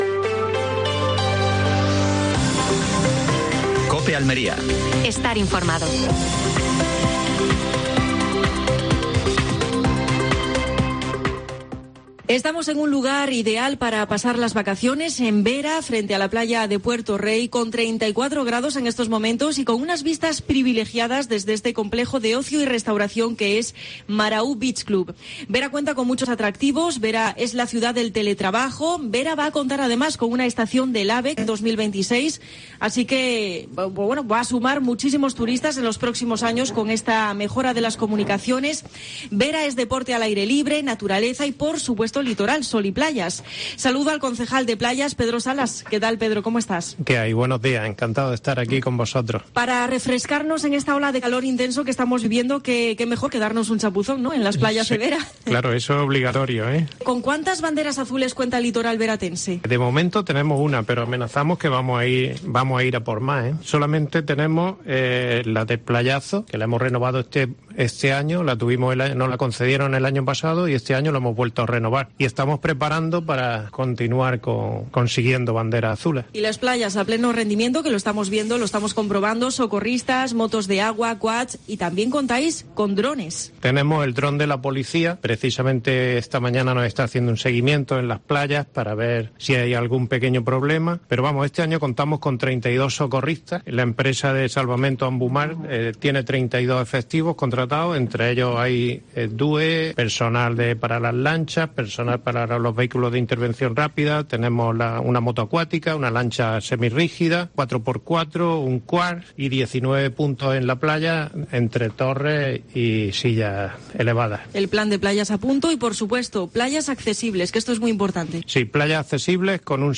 Entrevista a Pedro Salas (concejal de Playas en Vera).